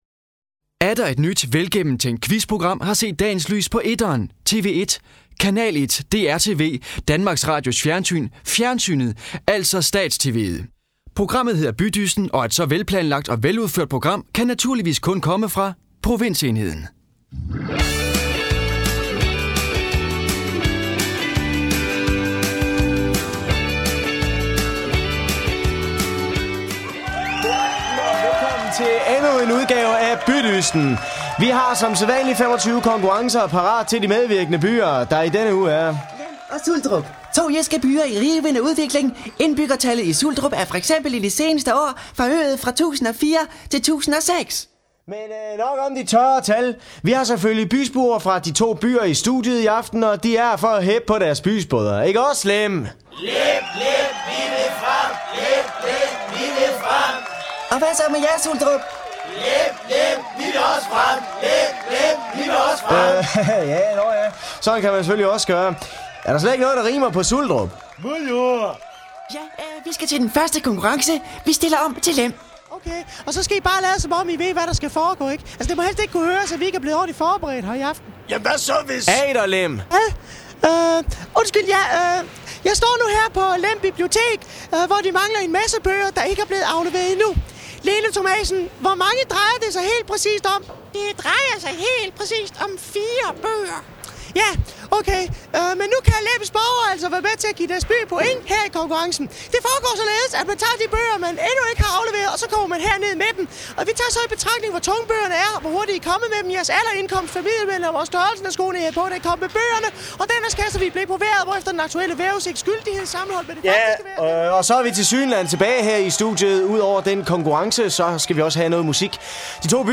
Det er her, du kan høre alle de gode, gamle indslag fra ANR's legendariske satireprogram.